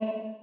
hard_drop.wav